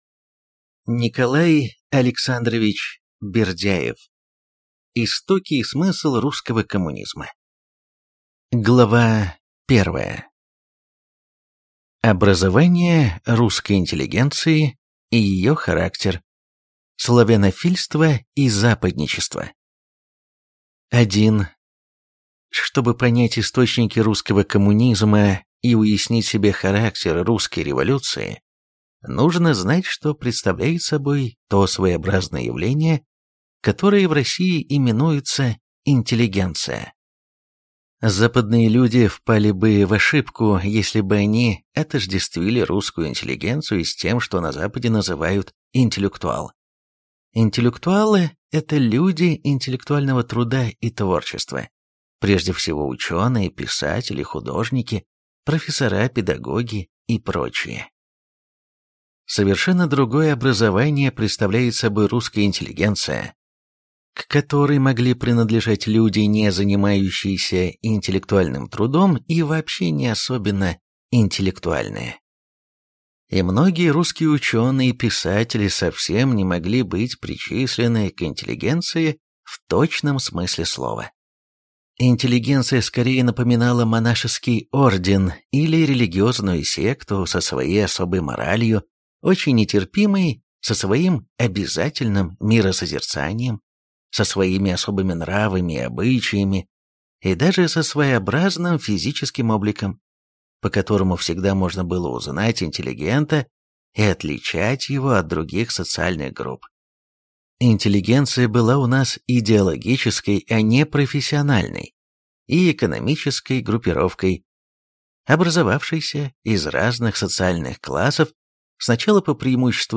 Аудиокнига Истоки и смысл русского коммунизма | Библиотека аудиокниг